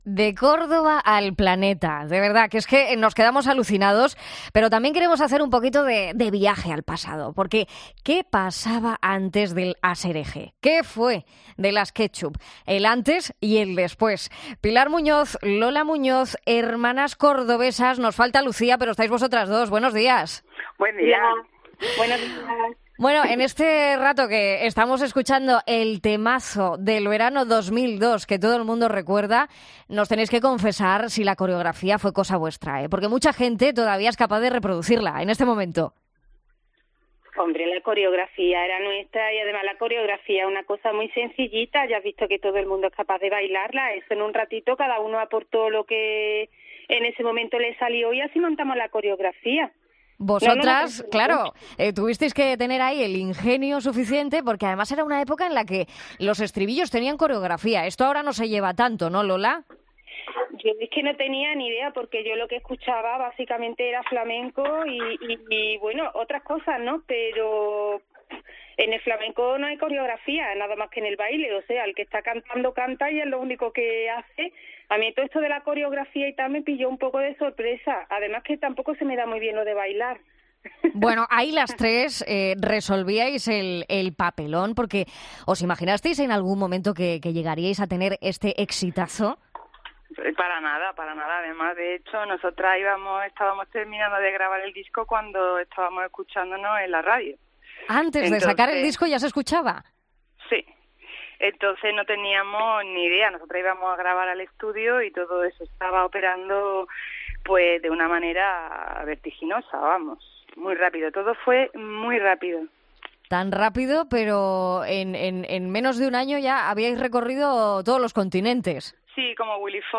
ESCUCHA ENTREVISTA COMPLETA | Pilar y Lola Muñoz de "Las Ketchup"